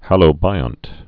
(hălō-bīŏnt)